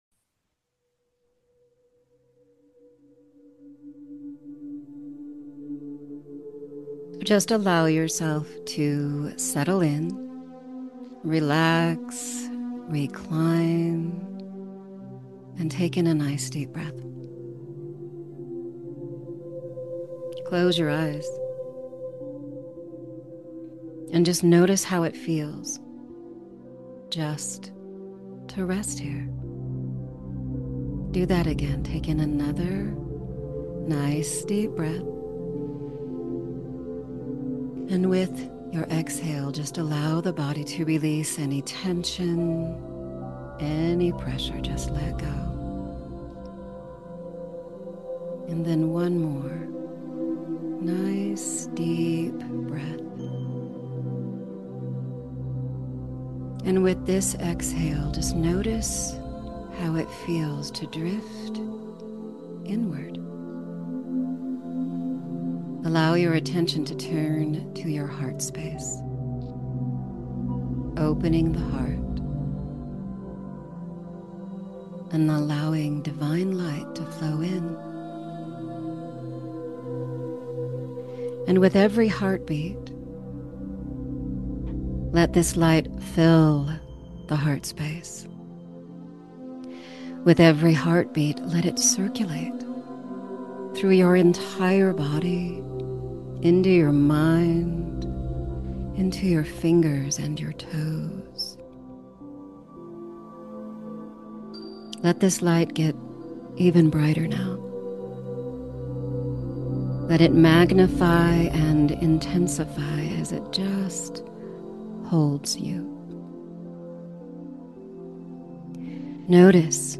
Allow my voice to guide you through a deep energy body healing and reset, resotration, and aura fortification. Allow the grace of the angels and higher divine spirits guide this healing journey to help you feel more whole, grounded, connected, and empowered.